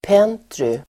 Ladda ner uttalet
pentry substantiv, kitchenette Uttal: [p'en:try (el. -i)] Böjningar: pentryt, pentryn, pentryna Definition: litet kök (also: galley) kitchenette substantiv, pentry , litet kök , kokvrå Förklaring: also: galley